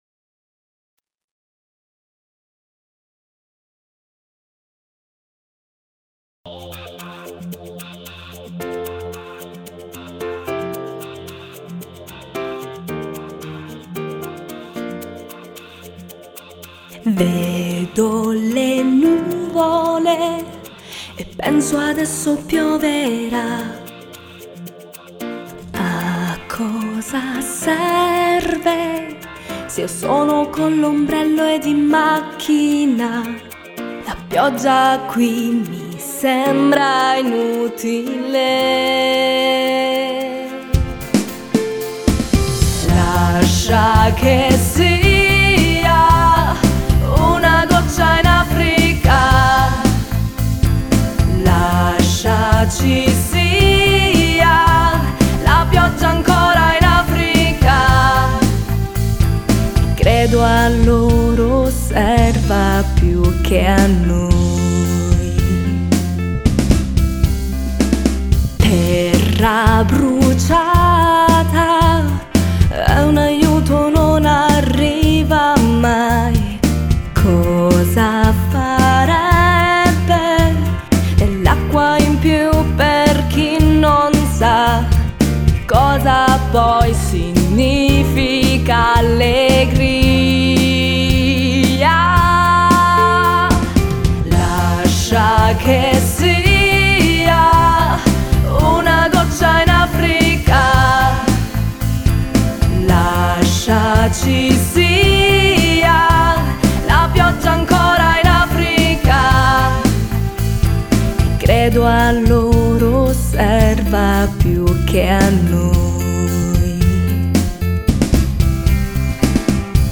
GenerePop